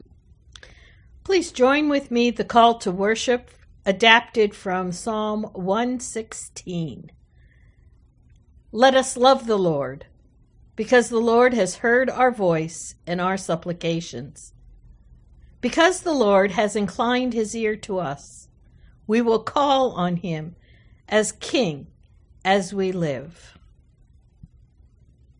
Opening Hymn: No. 454 - Open My Eyes, That I May See